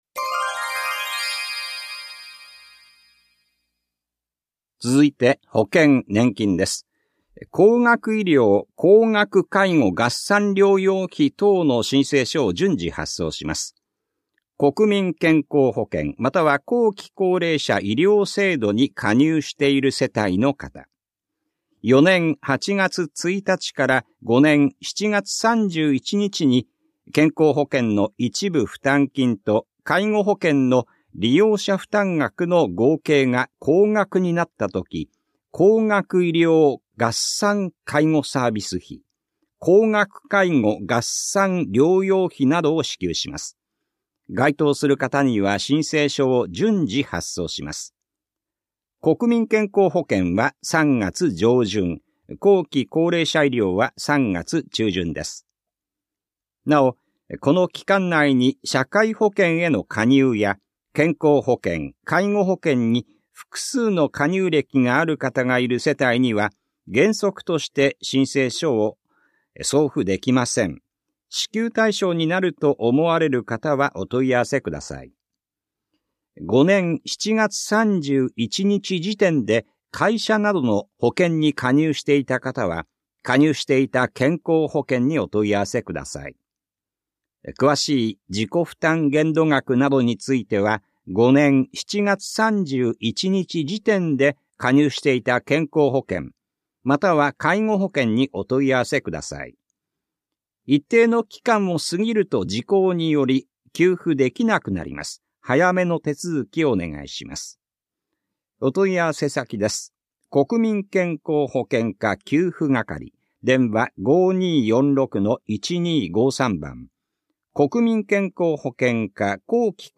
広報「たいとう」令和6年2月20日号の音声読み上げデータです。